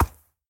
horse